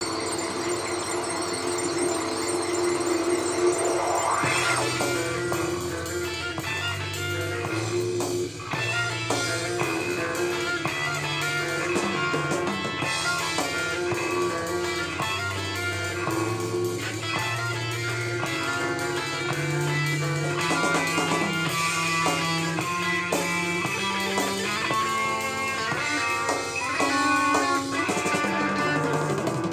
このアルバムジャケットの画像をクリックすると、AudiqのCDプレーヤーで再生した時の音が流れます。
前述の、CD、NAS、Room Fitの時の音源(空気録音)は、このマイクをPCに繋いで録っております。